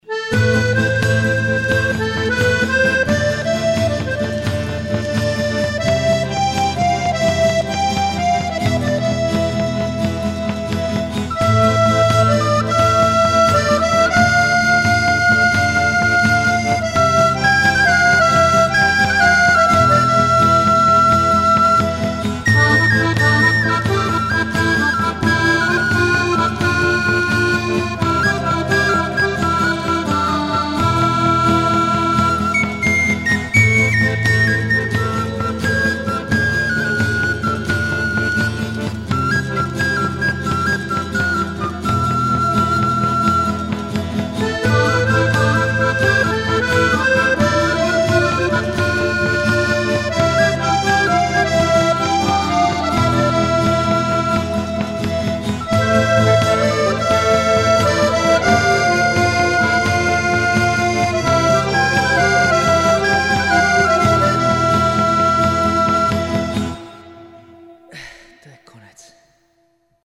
živě "all in one"